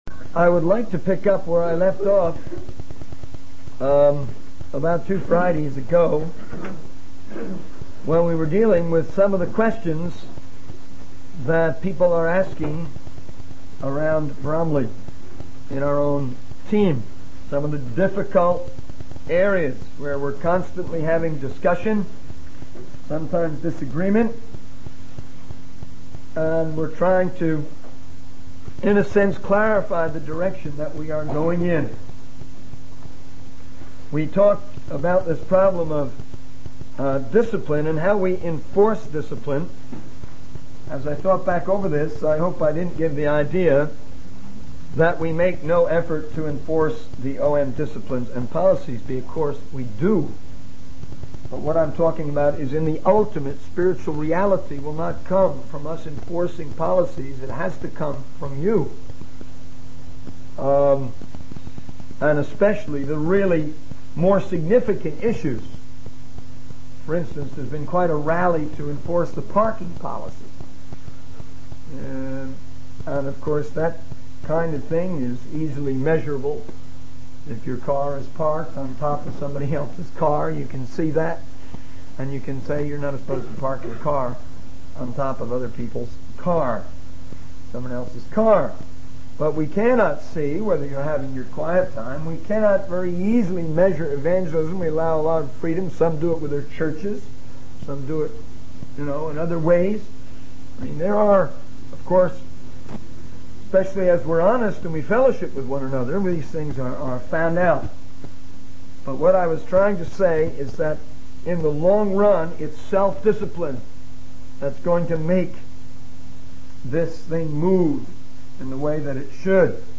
In this sermon, the speaker addresses the issue of discipline within the church community. While the church does enforce policies and disciplines, the ultimate spiritual growth and discipline must come from the individuals themselves. The speaker emphasizes that each person should be treated individually according to their needs, whether it be physical, financial, or emotional.